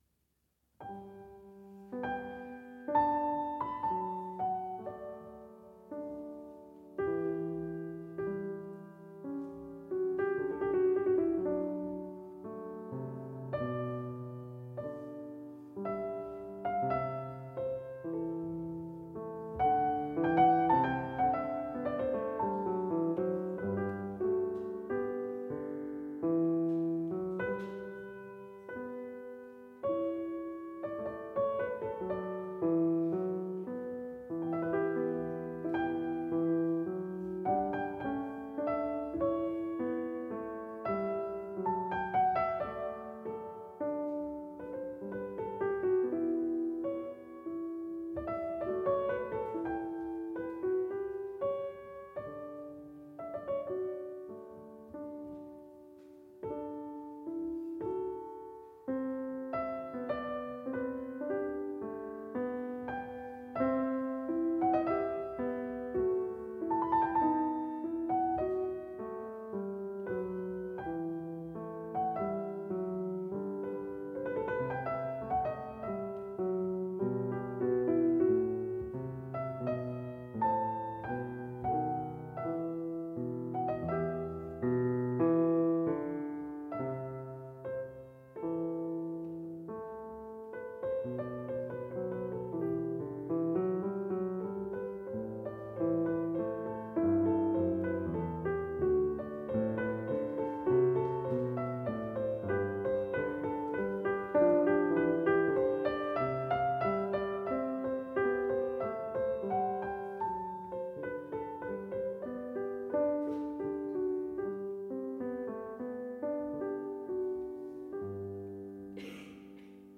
Live Recordings:
24/09/2013 - Teatro degli Atti, Rimini (Italy)